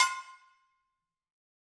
6-brakedrum.wav